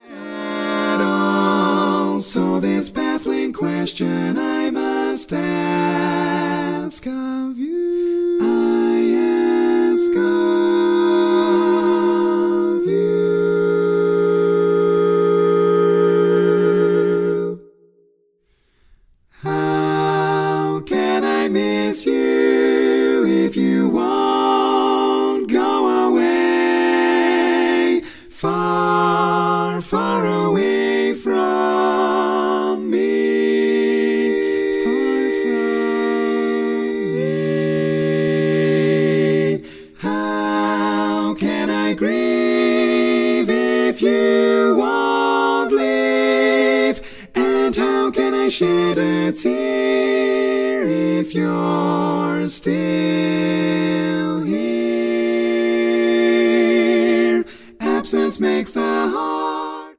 Category: Female